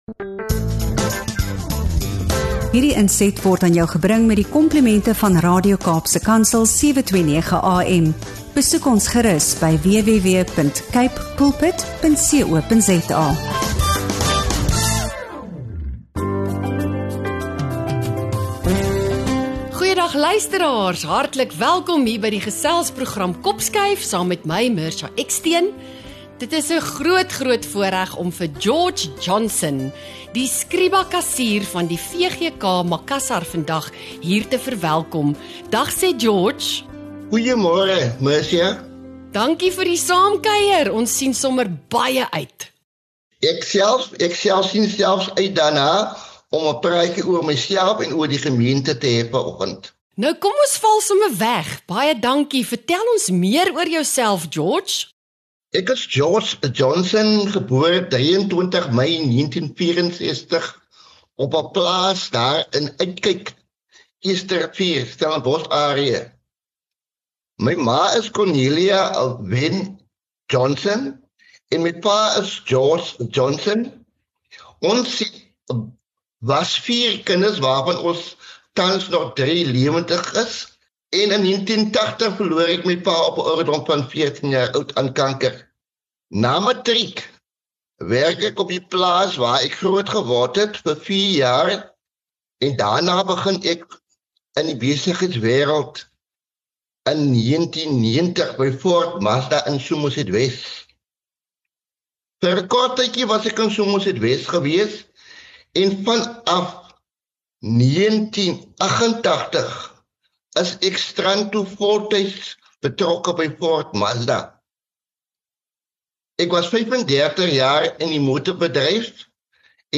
Hierdie gesprek belig hoe geloof, aksie en gemeenskap hand aan hand kan gaan.